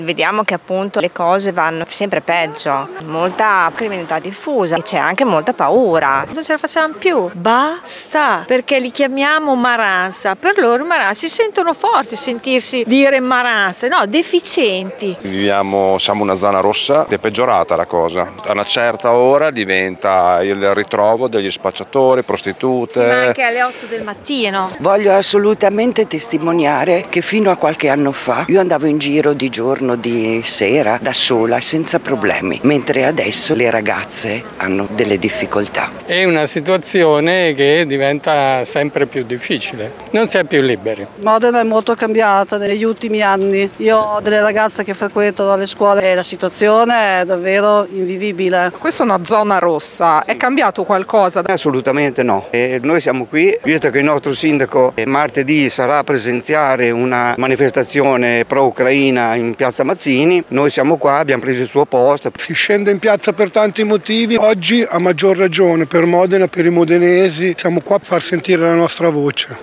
Successo per la manifestazione in zona tempio stazione organizzata oggi dal comitato Modena Merita di più. Circa 300 i partecipanti per chiedere interventi decisi per la sicurezza.
VOX-TEMPIO-STAZIONE.mp3